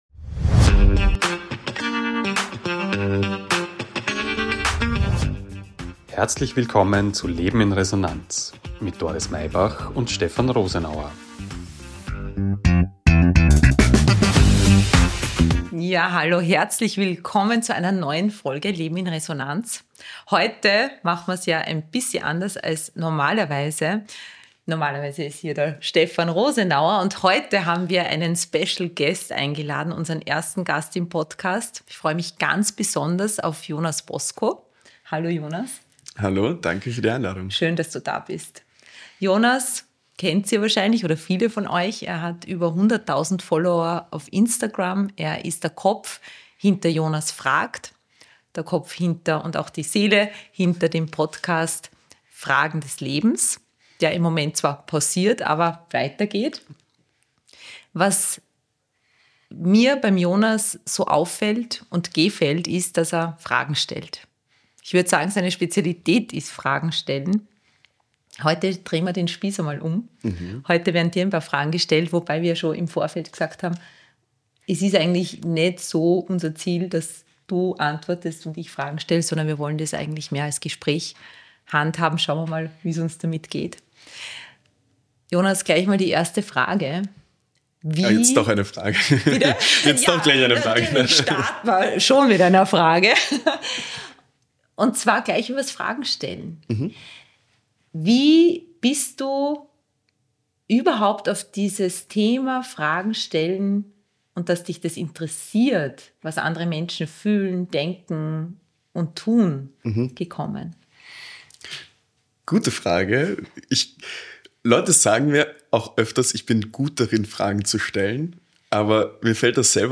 Ein inspirierendes Gespräch über Verletzlichkeit, Haltung und die Kraft guter Fragen.